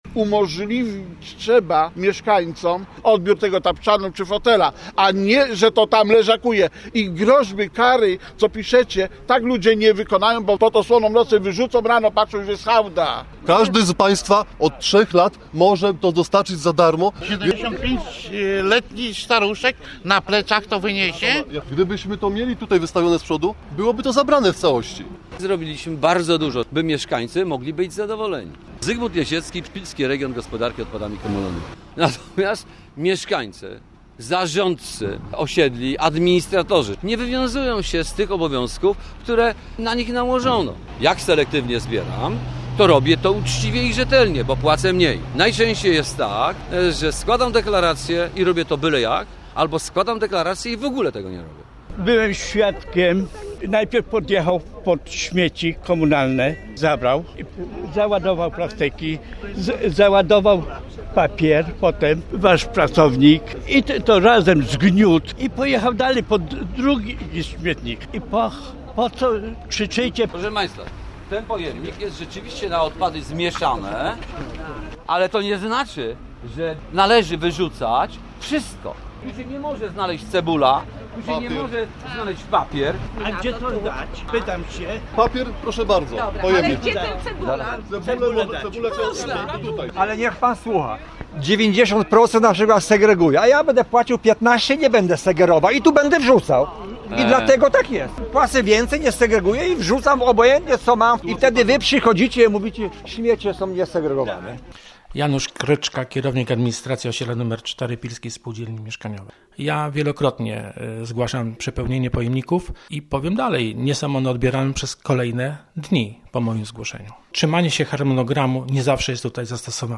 Miała być edukacyjna pogadanka, a była ostra dyskusja.
Doszło do niej w Pile podczas spotkania tych co wywożą śmieci i tych co je wyrzucają.